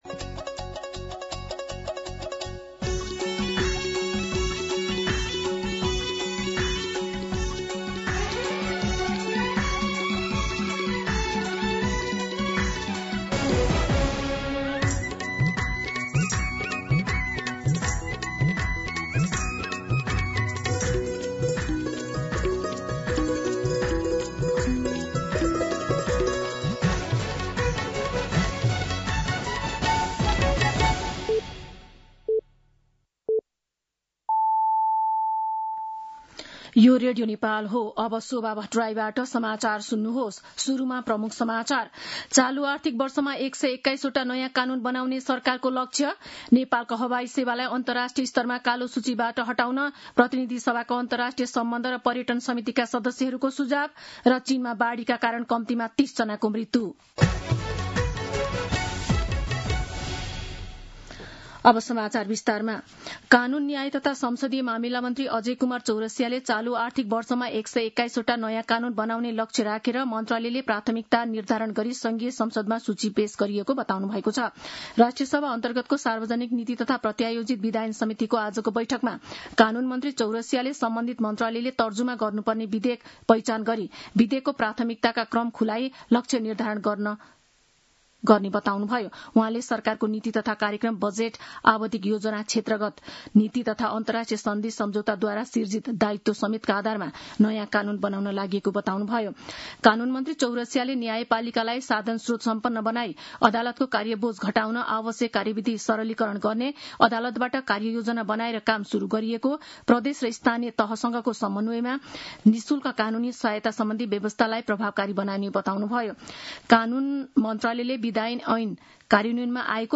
दिउँसो ३ बजेको नेपाली समाचार : १३ साउन , २०८२